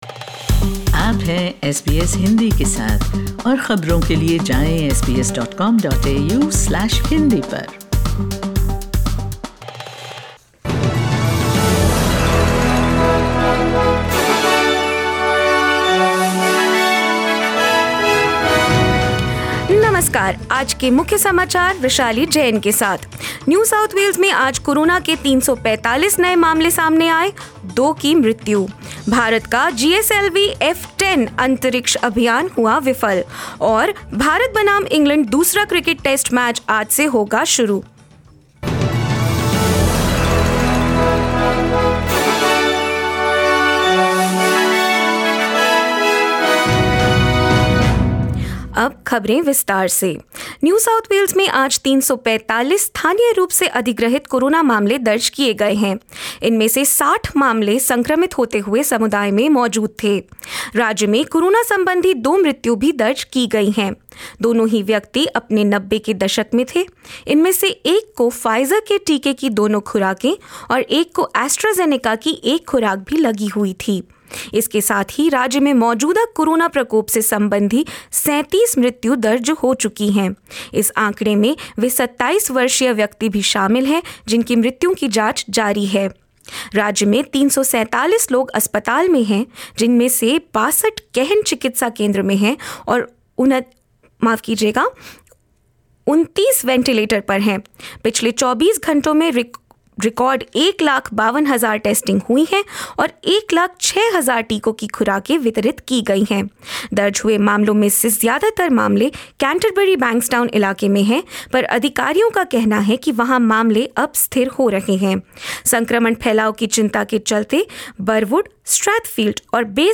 In this latest SBS Hindi News bulletin of Australia and India: Queensland records 10 new locally acquired cases linked to known outbreaks; NSW records 344 new locally acquired cases and two deaths, with at least 60 in the community while infectious and more